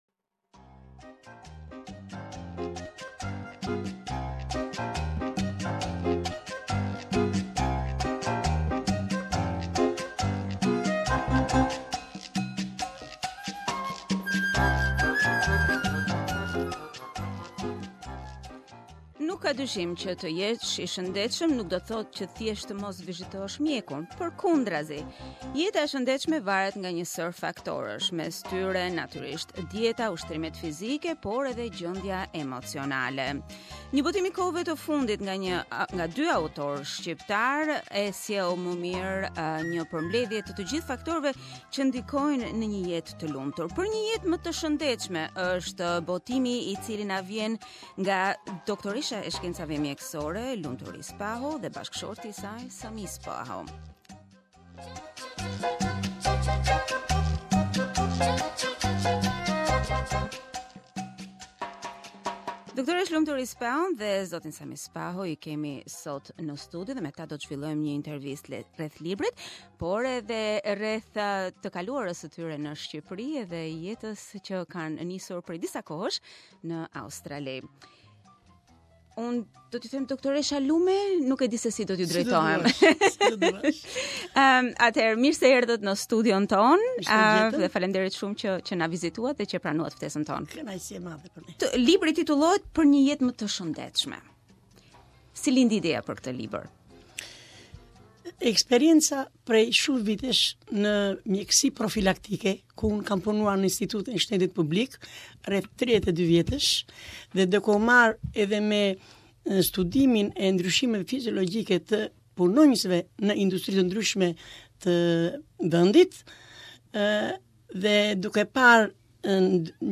We interviewed the authors who spoke not only about the book but also about their experiences as migrants in Australia.